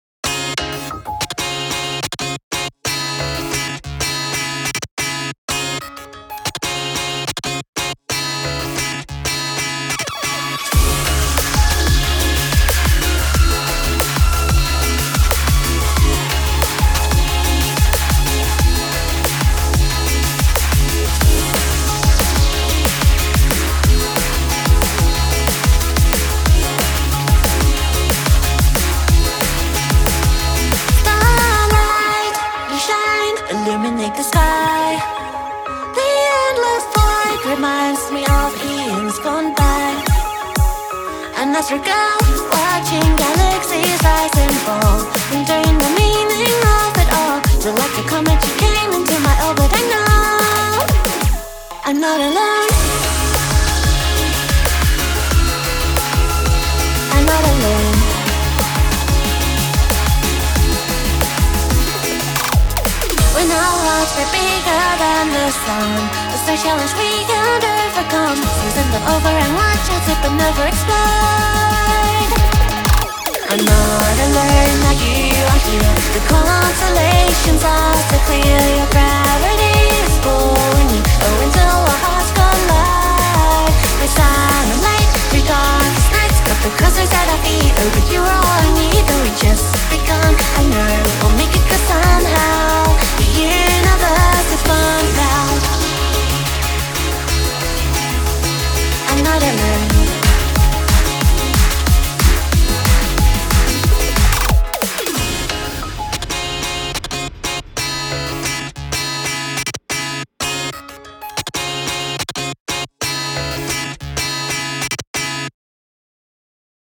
BPM92-183
Audio QualityMusic Cut